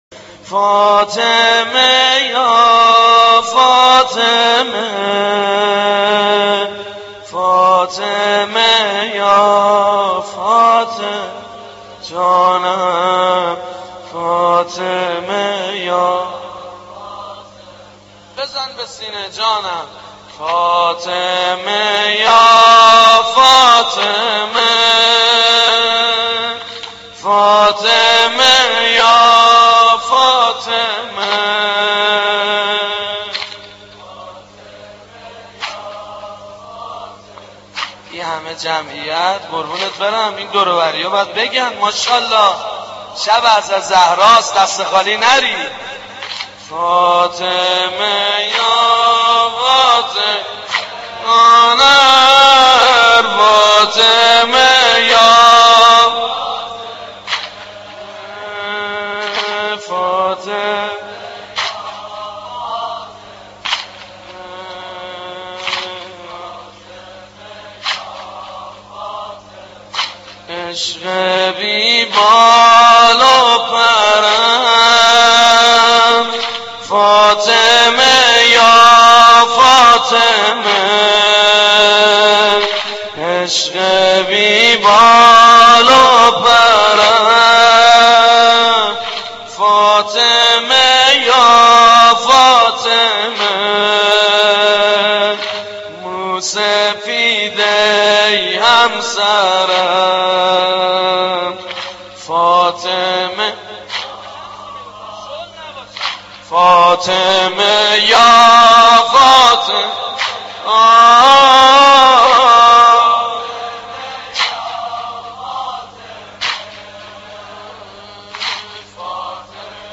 سینه زنی سید مجید بنی فاطمه در سوگ شهادت بانوی مظلومه حضرت فاطمه زهرا (س) (4:54)